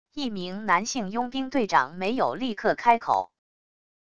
一名男性佣兵队长没有立刻开口wav音频